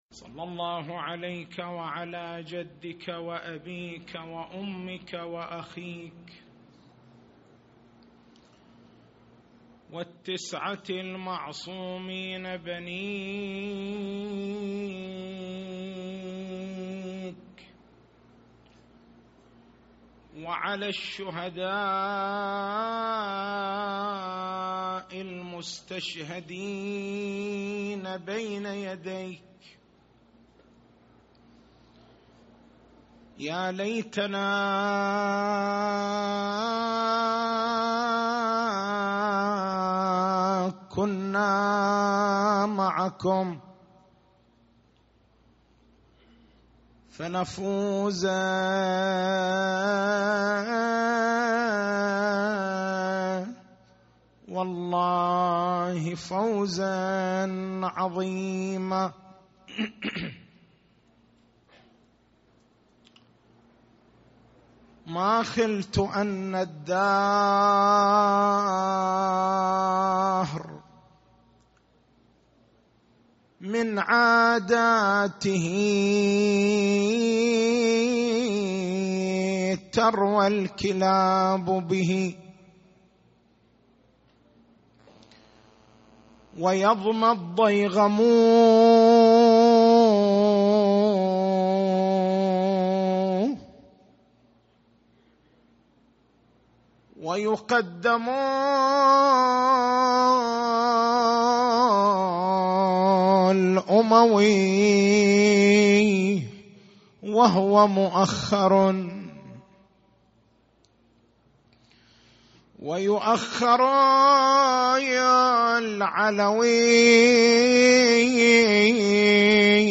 نص المحاضرة